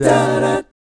1-CMI7    -L.wav